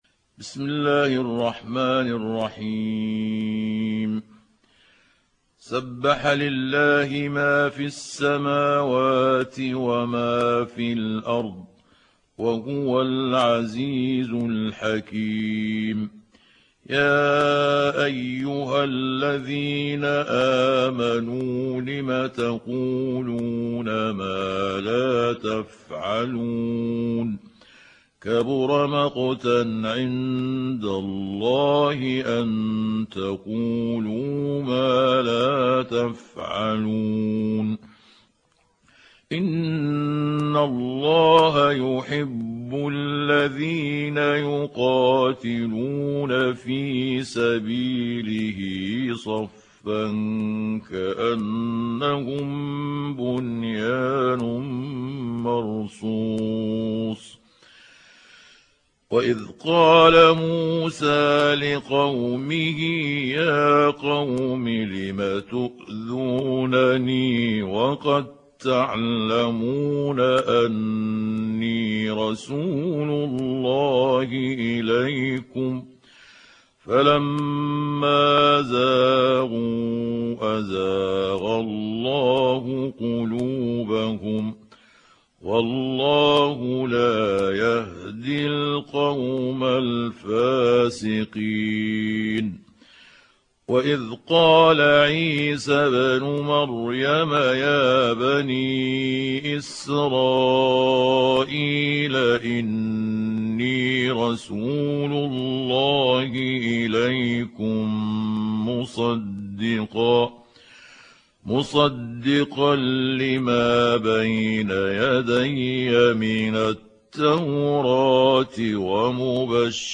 تحميل سورة الصف mp3 بصوت محمود عبد الحكم برواية حفص عن عاصم, تحميل استماع القرآن الكريم على الجوال mp3 كاملا بروابط مباشرة وسريعة